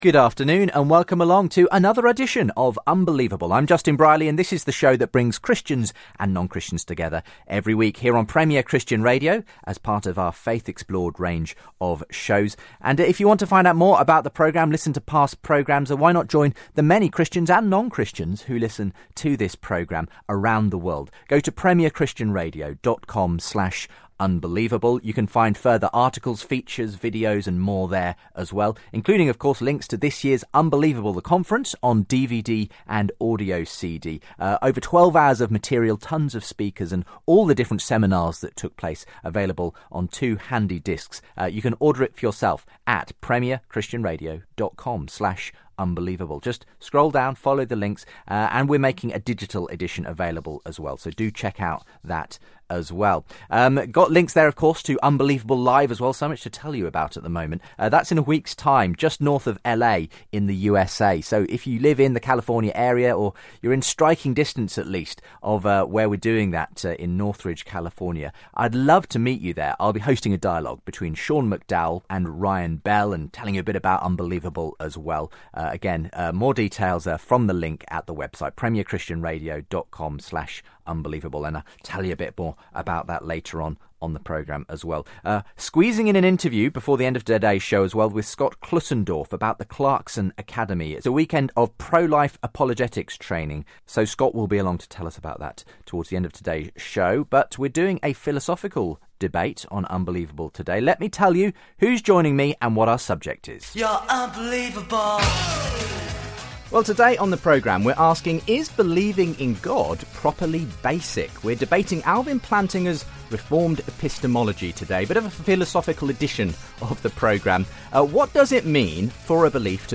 Christianity, Religion & Spirituality 4.6 • 2.3K Ratings 🗓 19 August 2016 ⏱ 89 minutes 🔗 Recording | iTunes | RSS 🧾 Download transcript Summary Today’s show debates philosopher Alvin Plantinga’s reformed epistemology and his view that Christians have a ‘properly basic’ belief in God. Can Christians can be justified in their belief in God apart from argumentation?